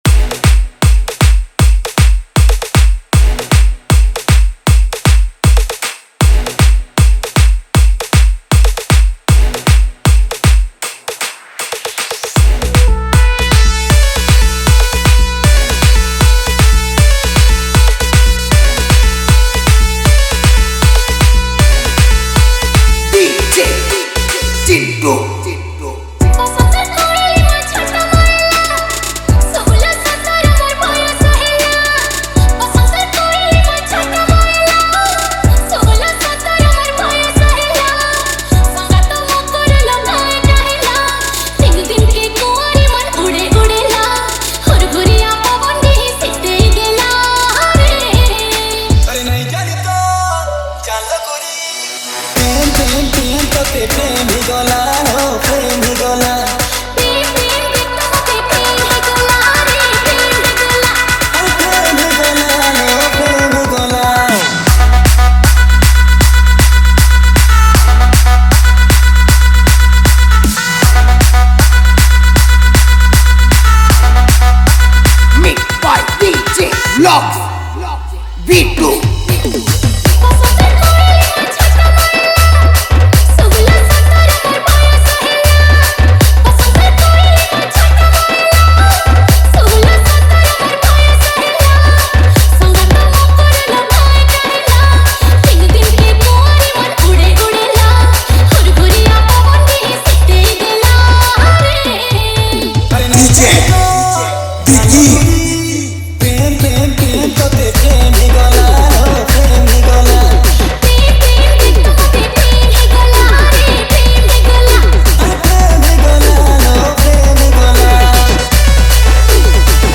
Sambalpuri Dj Song 2025
Category:  Sambalpuri Dj Song 2025